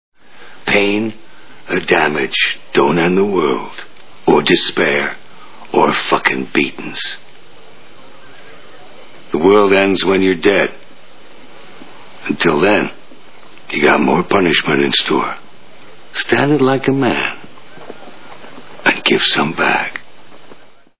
Deadwood TV Show Sound Bites